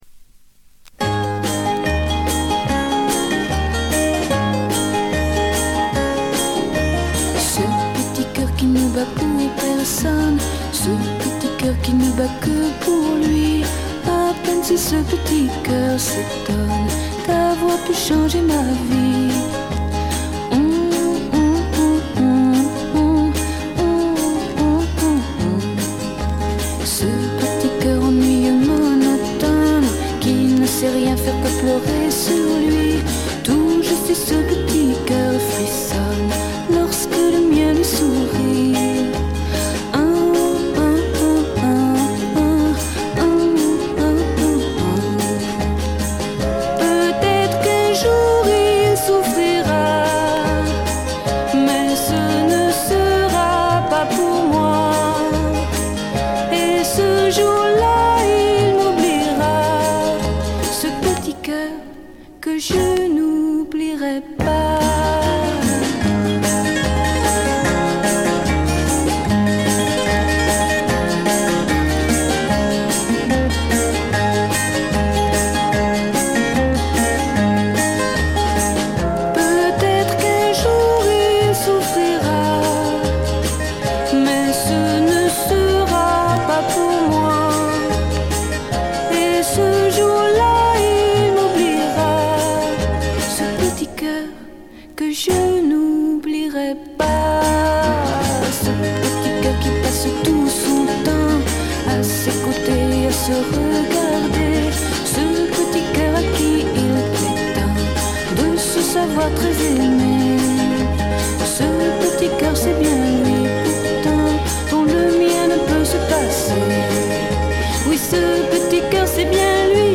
モノラル盤。
試聴曲は現品からの取り込み音源です。